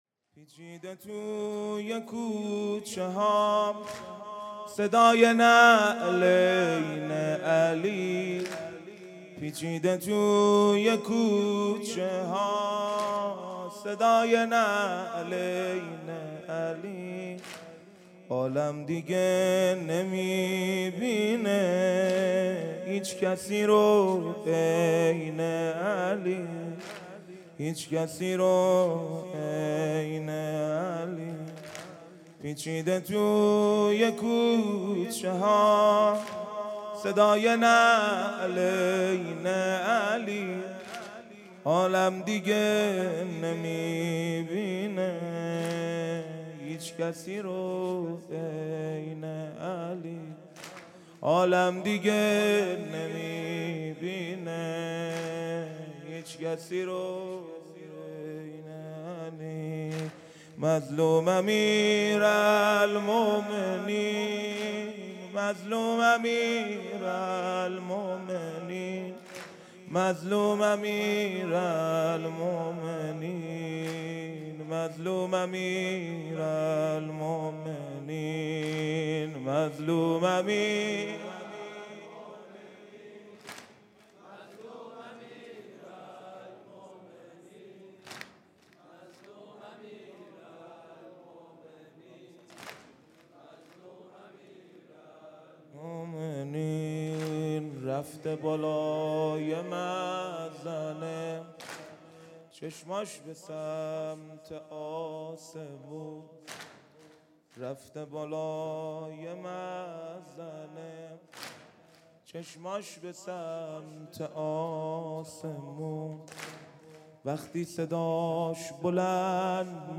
هیئت دانشجویی فاطمیون دانشگاه یزد
پیچیده توی کوچه ها|شب 21 رمضان